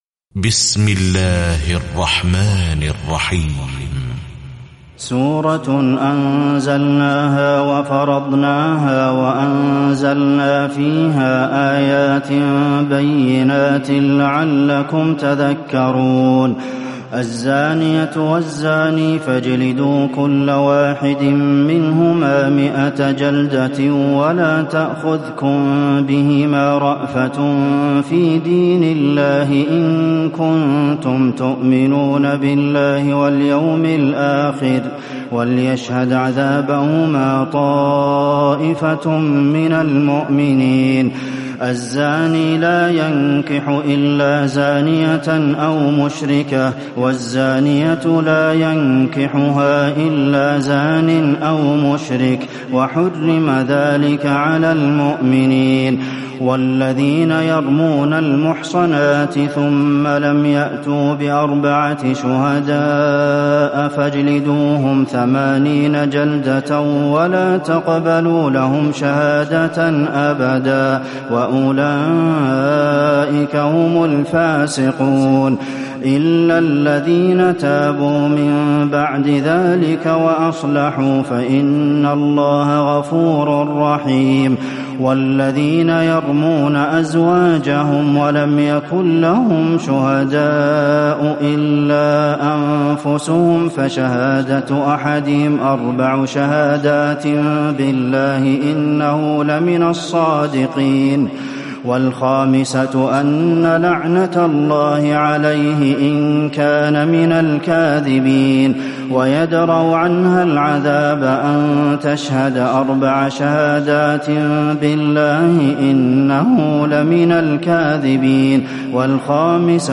تراويح الليلة السابعة عشر رمضان 1438هـ سورة النور (1-64) Taraweeh 17 st night Ramadan 1438H from Surah An-Noor > تراويح الحرم النبوي عام 1438 🕌 > التراويح - تلاوات الحرمين